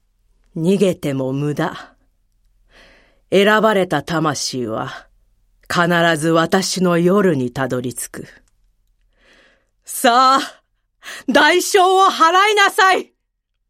セリフ3
ボイスサンプル